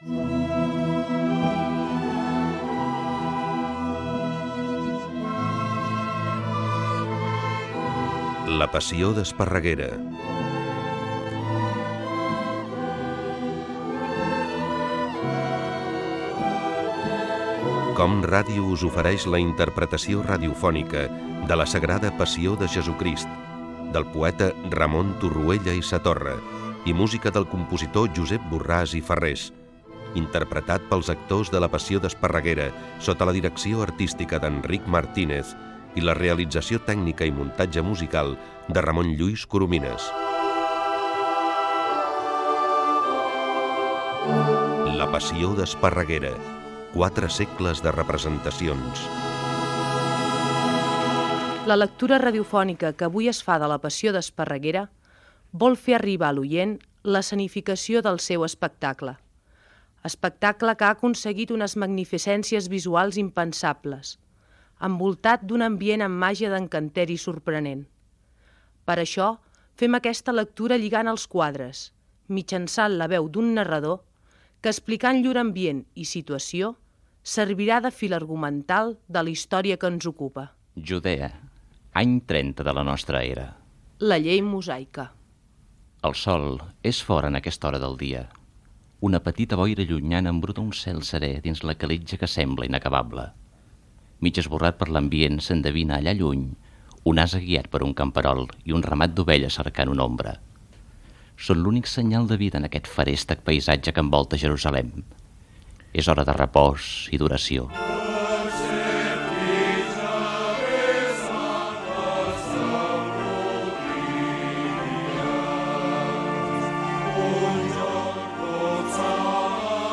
Ficció
FM